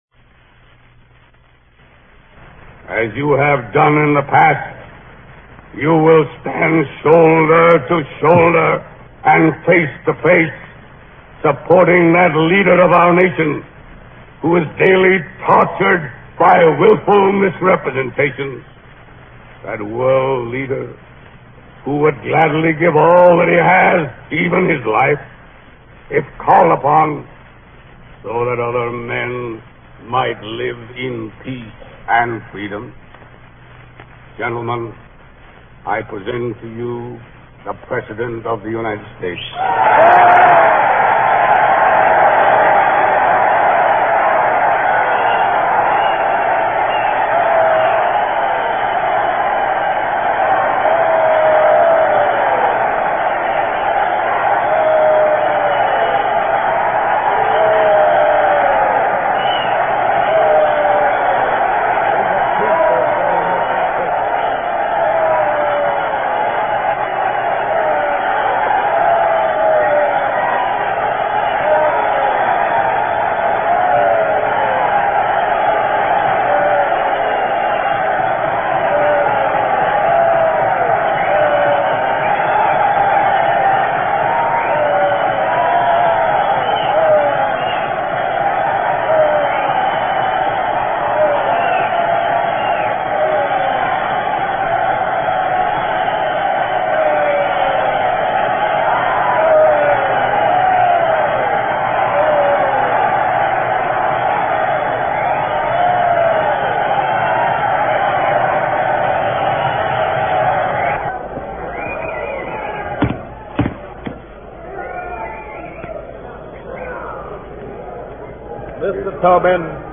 President Franklin Delano Roosevelt opens the campaign for his fourth term with a speech in Washington, D.C., delivered to the International Brotherhood of Teamsters. He attacks the Republican party for its non-progressive attitude, rebuffs attacks made by Republicans that he sent a U.S. Destroyer to retrieve his dog, Fala, after leaving him on the Aleutian Islands. Introduction by Daniel J. Tobin, president of the International Brotherhood of Teamsters.